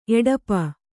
♪ eḍapa